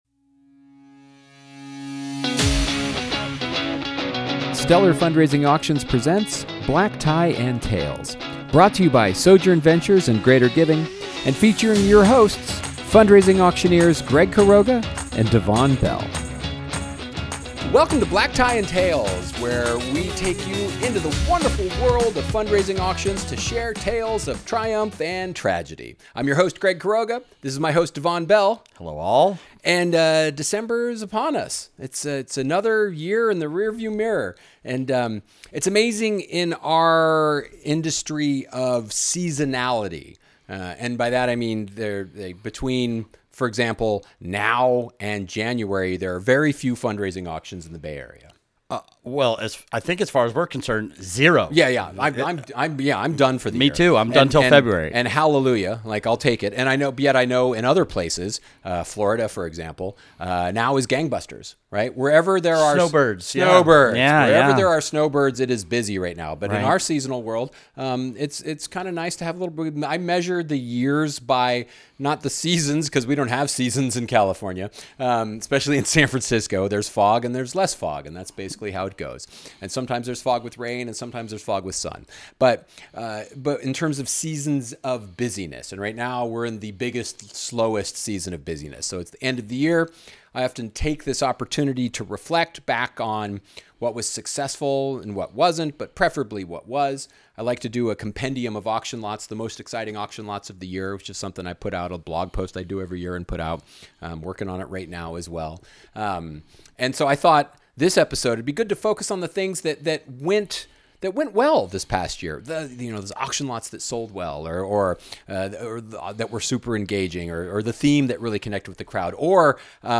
Take a peek behind the curtain of the wonderful world of fundraising auctions with two auctioneers who have seen it all.